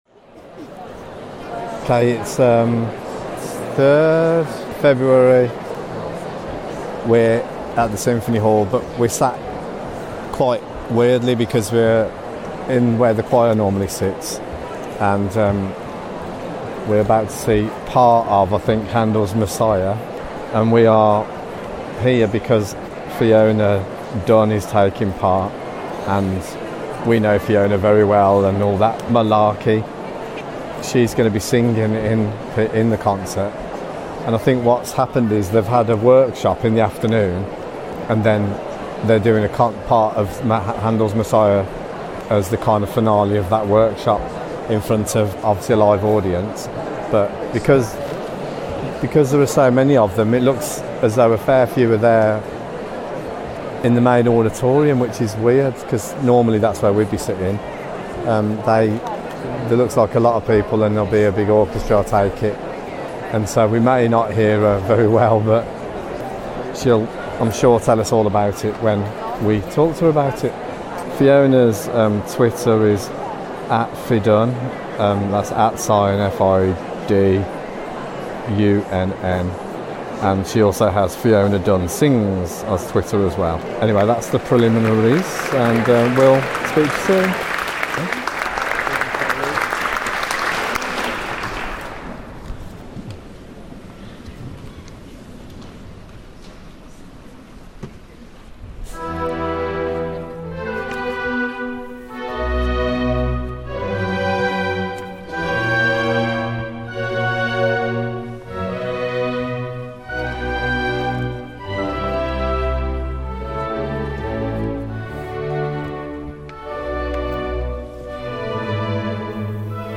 2013_02_03 Handles Masiah Recorded At Symphony Hall Birmingham (part 1)
Around 2,000 people from choirs all around the region had the opportunity to rehearse and perform it with renowned chorus director Simon Halsey, in the magnificent surroundings of Symphony Hall. Symphony Hall is a 2,262 seat concert venue located inside the International Convention Centre (IC